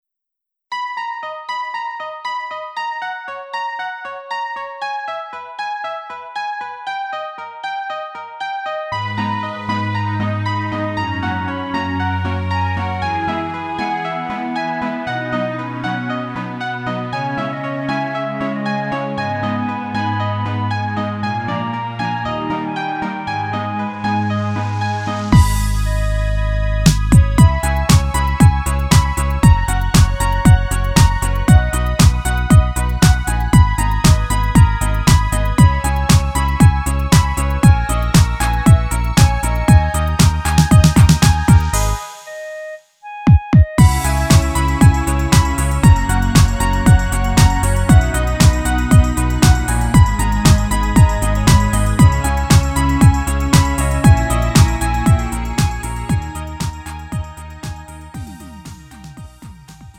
음정 원키 3:41
장르 가요 구분 Lite MR